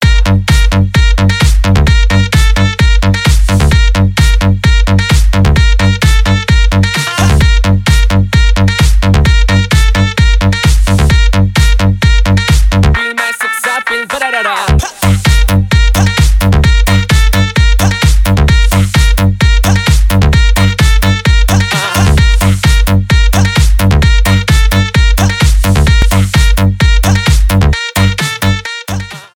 саксофон
club house